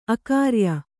♪ akārya